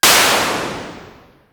ihob/Assets/Extensions/RetroGamesSoundFX/Shoot/Shoot15.wav at master
Shoot15.wav